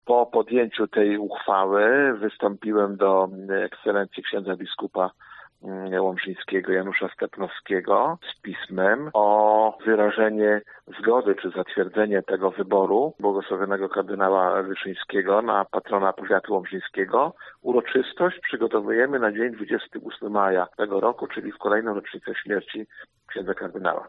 Starosta dodaje, że Rada powiatu przyjęła uchwałę w związku z wyrażeniem woli ustanowienia tego celu: